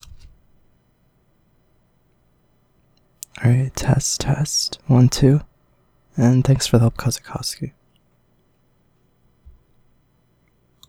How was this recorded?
Blue Yeti Problem? Hissing Sound Here is your recording, normalized (about 20 db of gain) and noise removal applied.